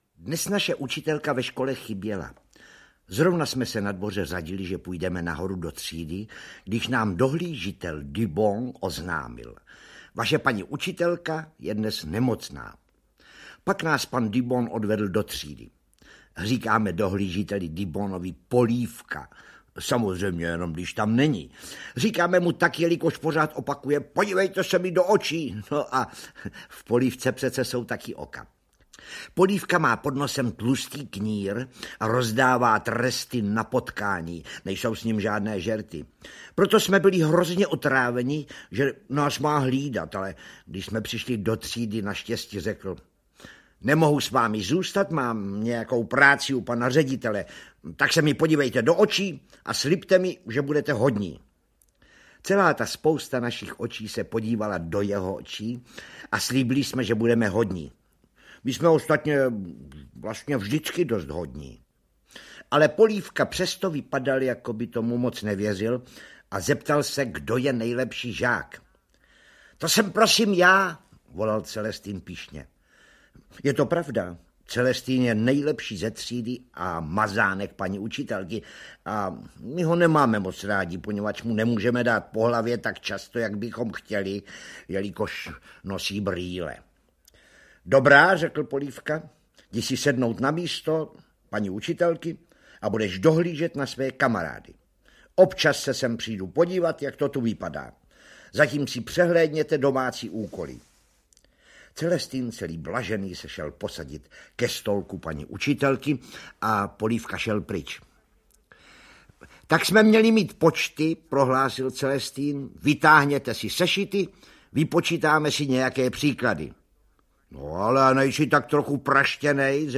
Mikulášovy patálie audiokniha
Ukázka z knihy